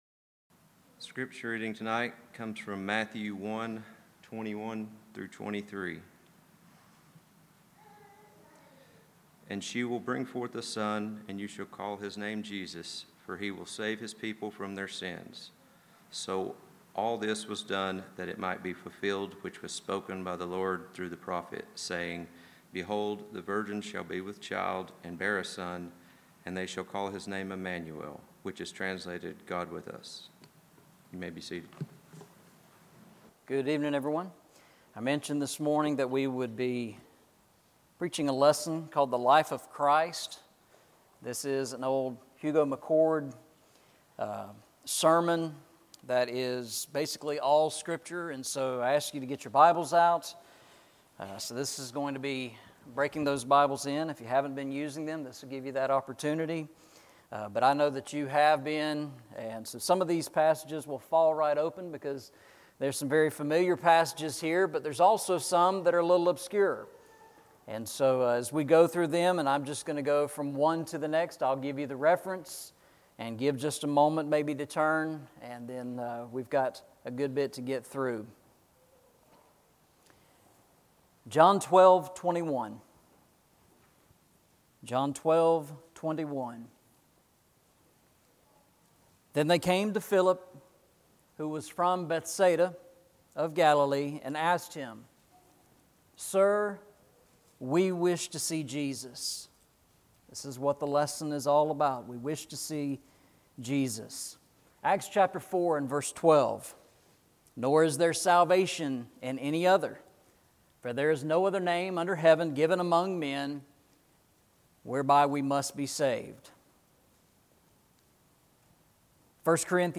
Eastside Sermons Passage: Matthew 1:21-23 Service Type: Sunday Evening « Walking Through the Bible